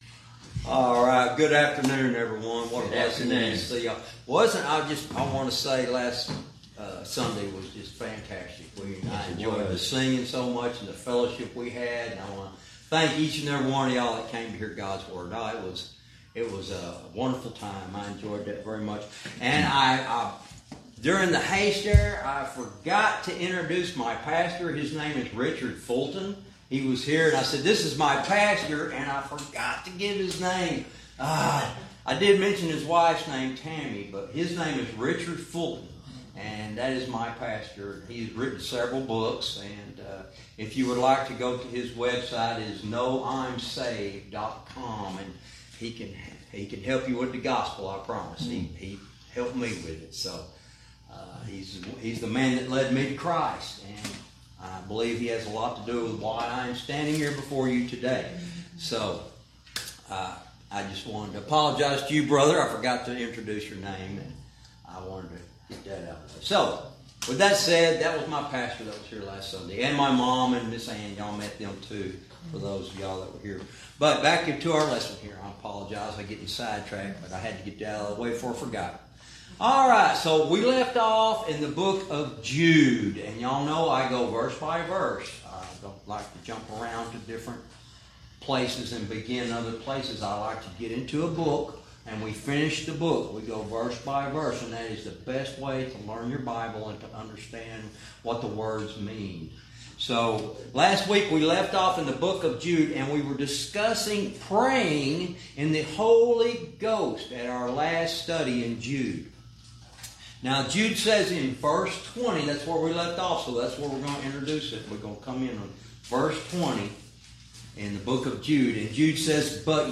Verse by verse teaching - Jude lesson 93 verse 20